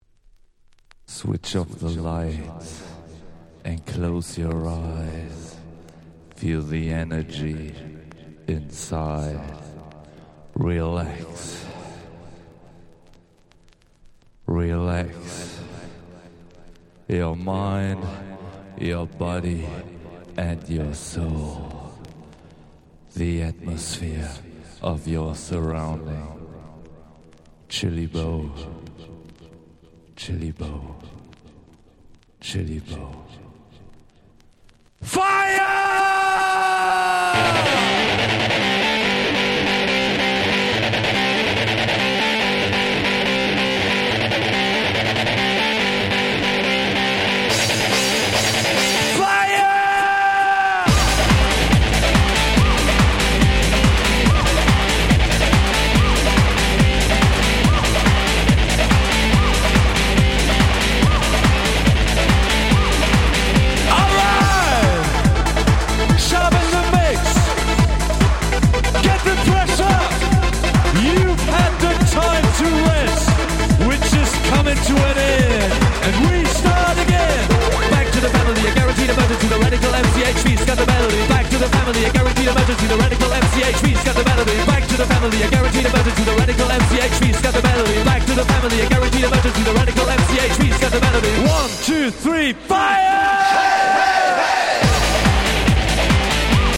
97' Dance Pop / Trance Super Hit !!